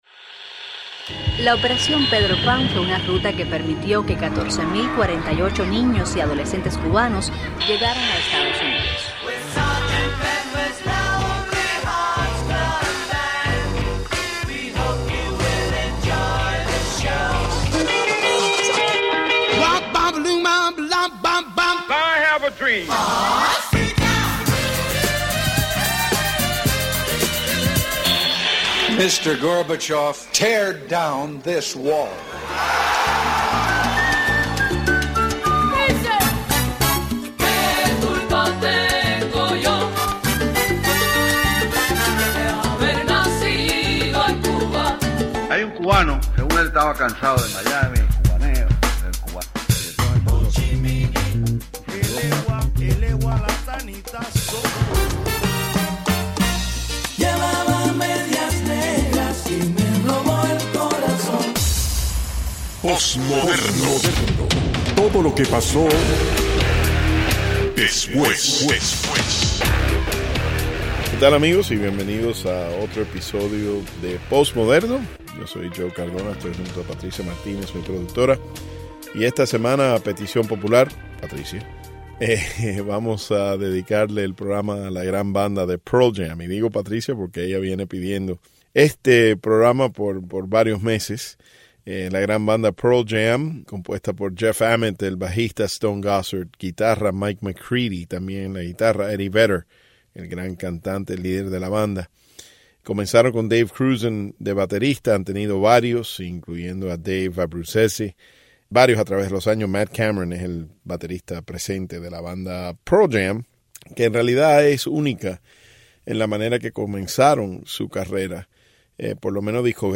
banda de rock